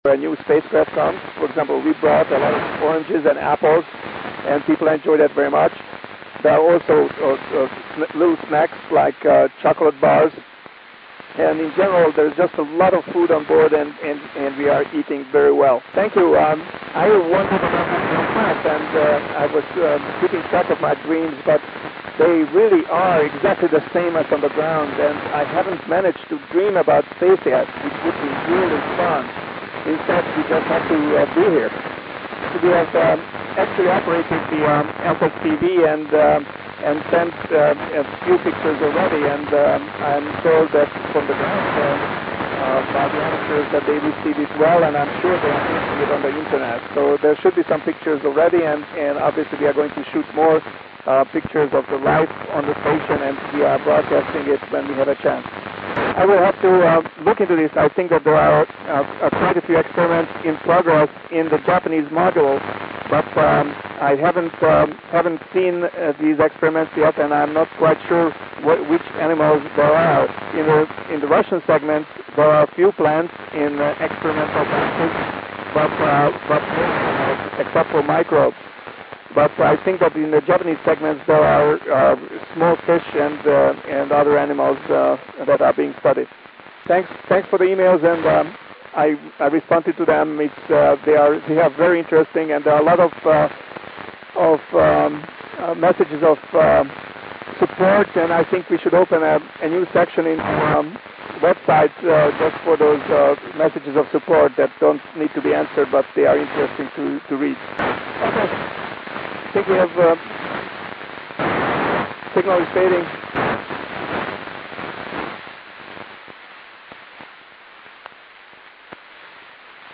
Начало » Записи » Записи радиопереговоров - МКС, спутники, наземные станции